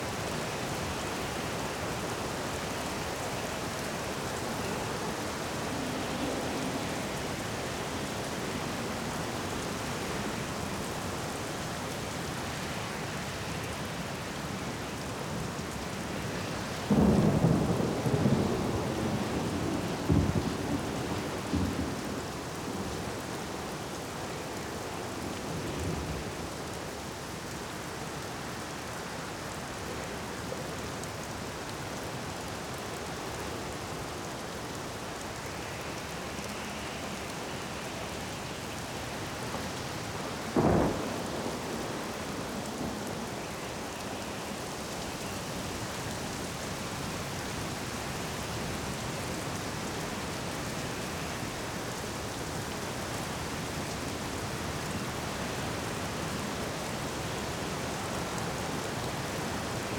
Sea Storm.ogg